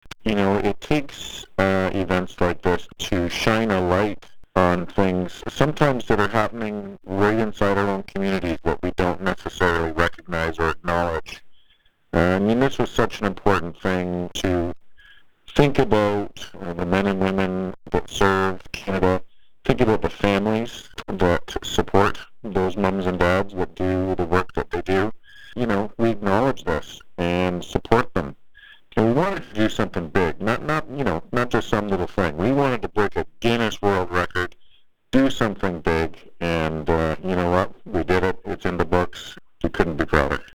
(note: poor audio quality)